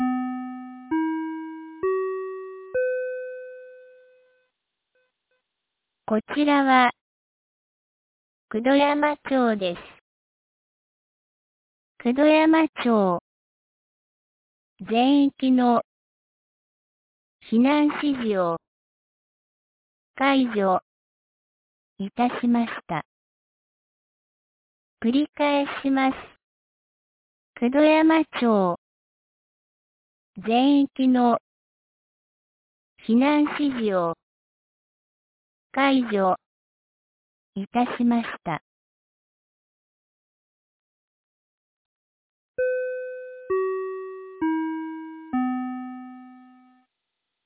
2023年06月03日 09時05分に、九度山町より全地区へ放送がありました。
放送音声